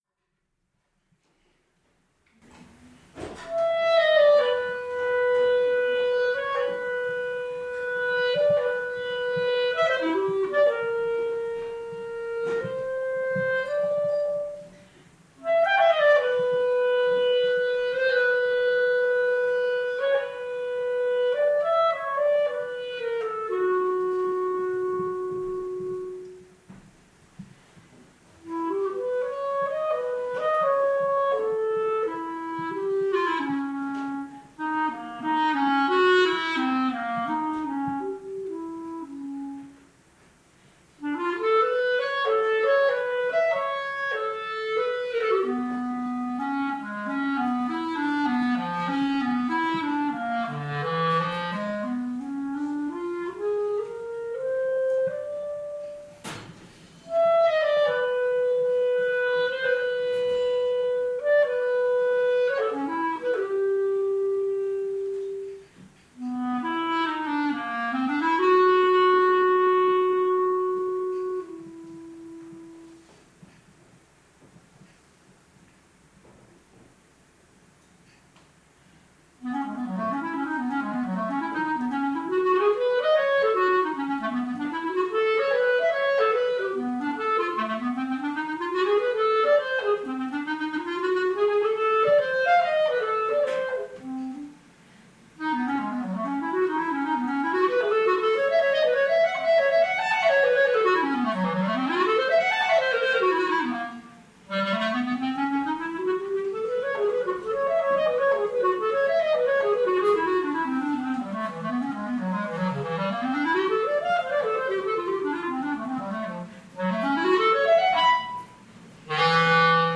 clarinet https